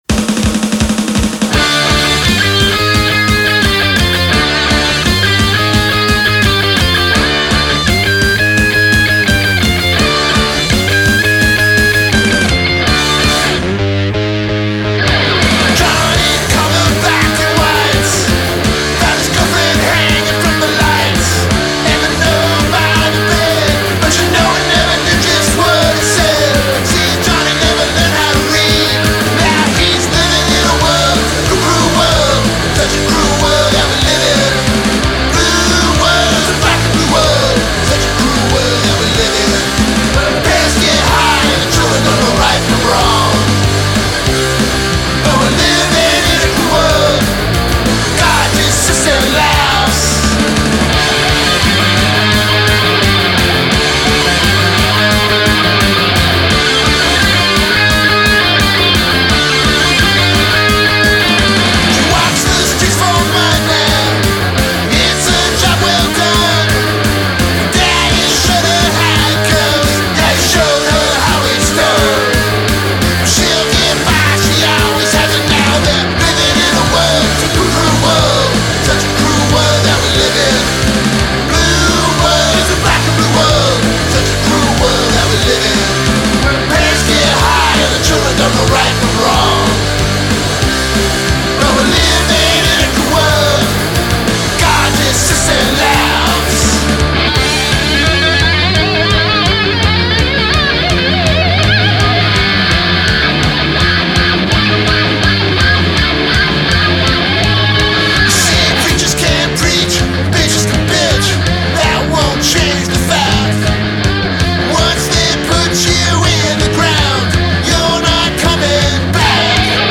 Recorded at Mole Tracks West